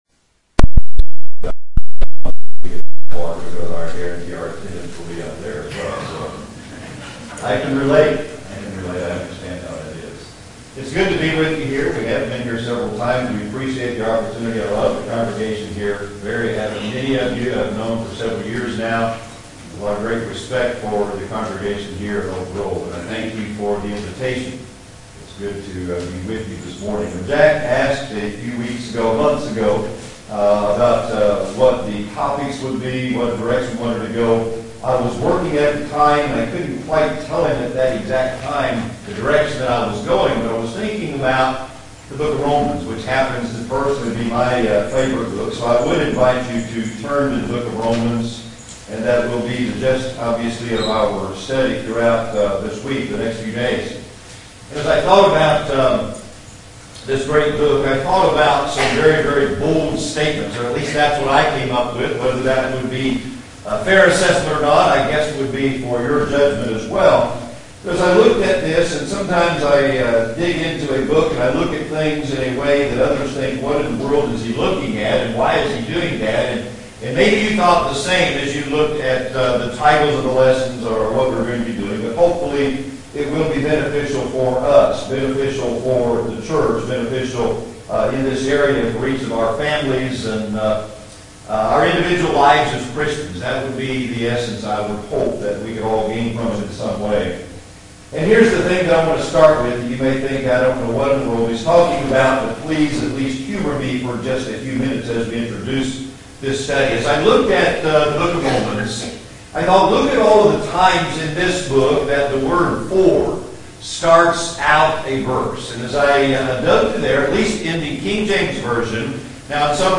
July 2, 2014 Series: Summer Sermon Series Romans 1:16 - 17 It's suggested by some that this is the theme of the entire book.